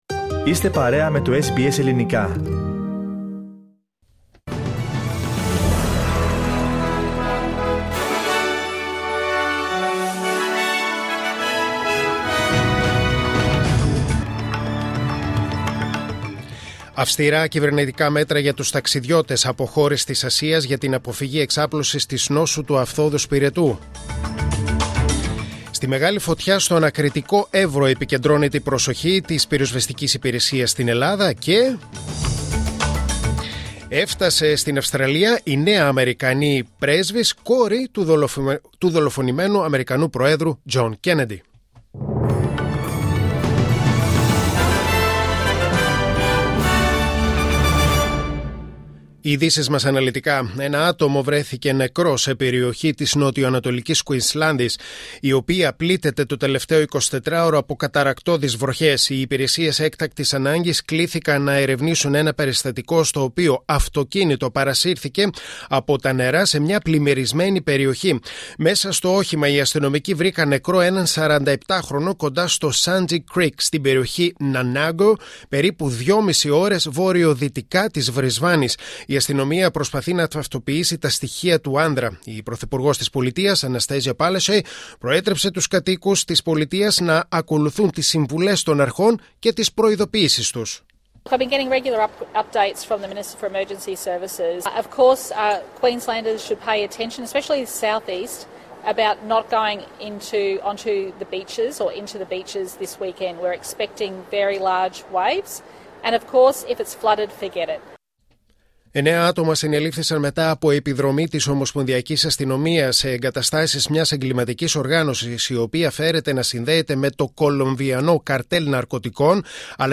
News in Greek: Friday 22.7.2022